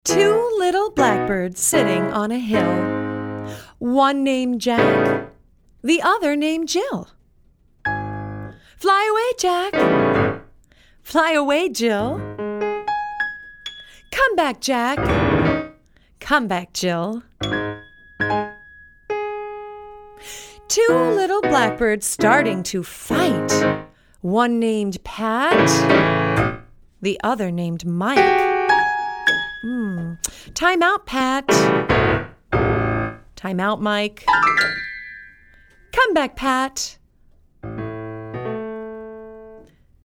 American Traditional